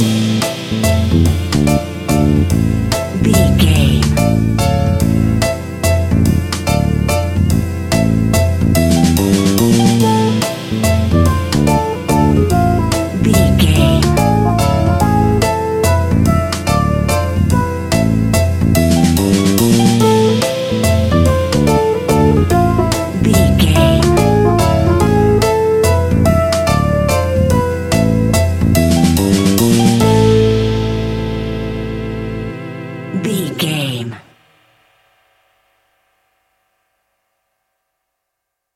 Aeolian/Minor
scary
eerie
playful
electric piano
drums
bass guitar
synthesiser
spooky
horror music